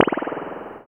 SI2 PLOP.wav